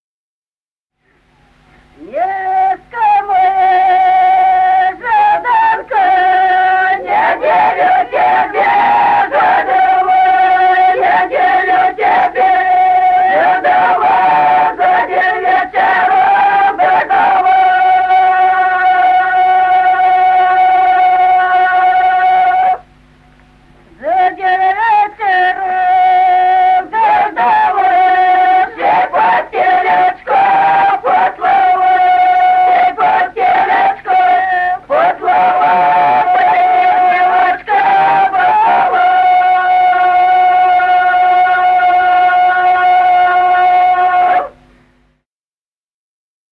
Народные песни Стародубского района «Невестка моя», свадебная.
c. Остроглядово.